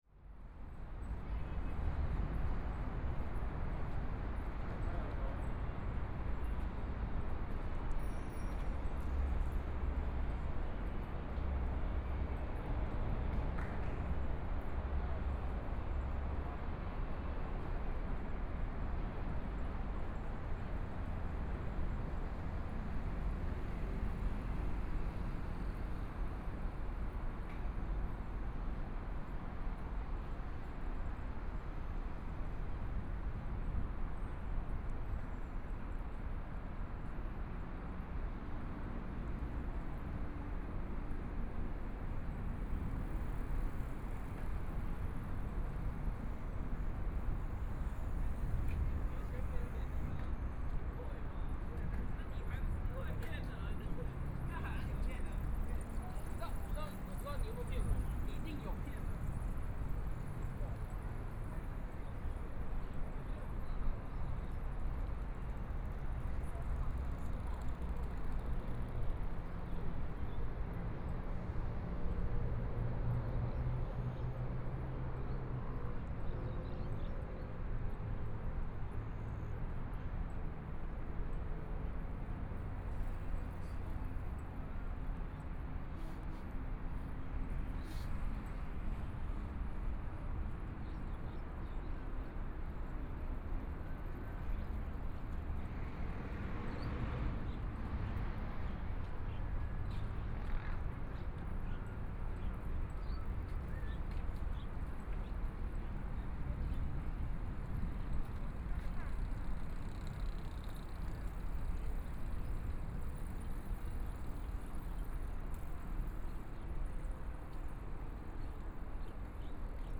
Walking along the river, Pedestrian ,Traffic Sound ,A lot of people riding bicycles through,Binaural recordings ,Best with Headphone, ( Proposal to turn up the volume )Zoom H4n+ Soundman OKM II